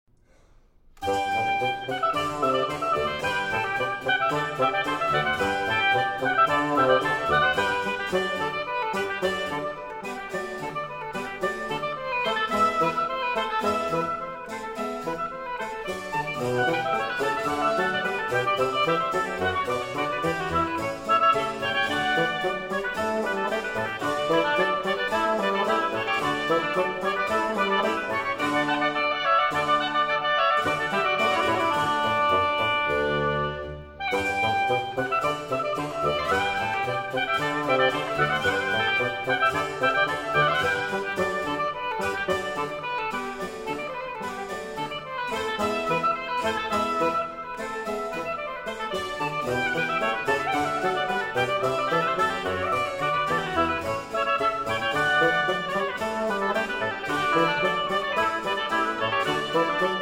Oboe
Bassoon
Harpsichord
from Trio Sonata in G Major